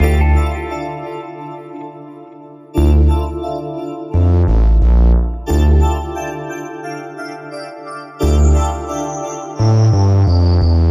没有谎言的钢琴循环曲
描述：悲伤/寒冷的Hip Hop/Trap循环
Tag: 85 bpm Hip Hop Loops Piano Loops 1.90 MB wav Key : F FL Studio